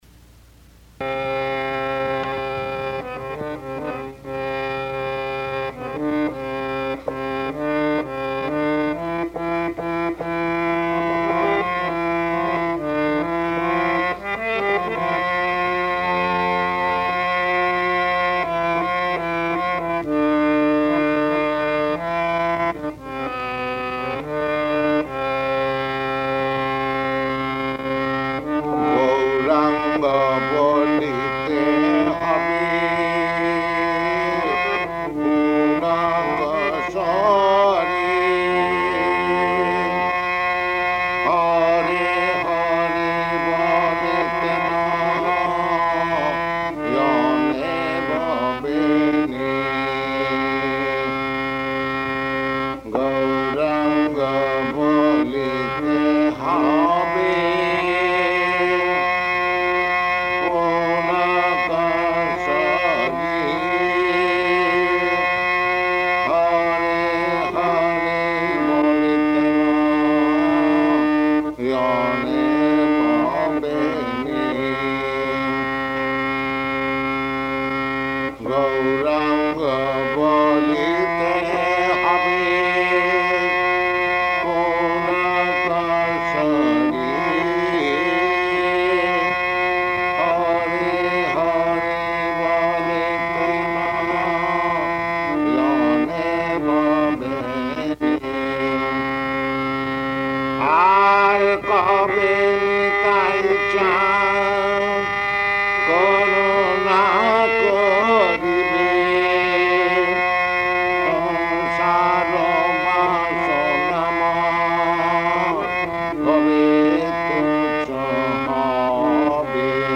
Type: Purport
Location: Los Angeles